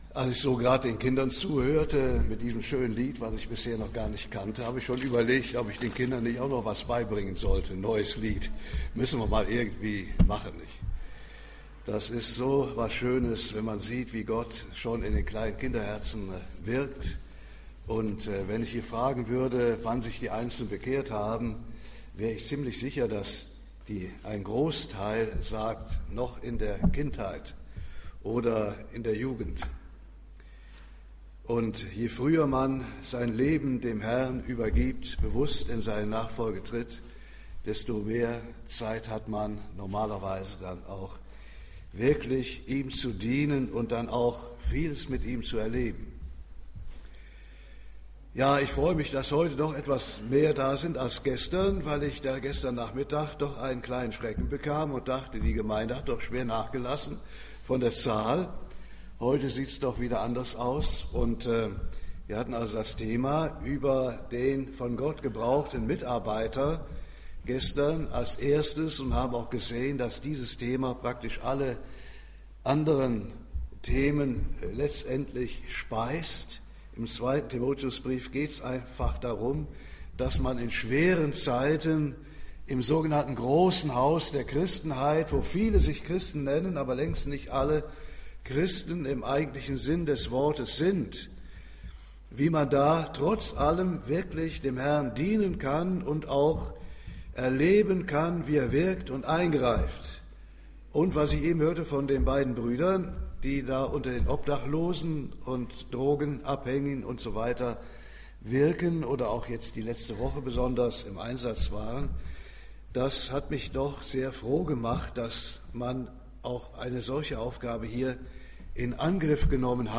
Predigten – Seite 14 – Arche Hannover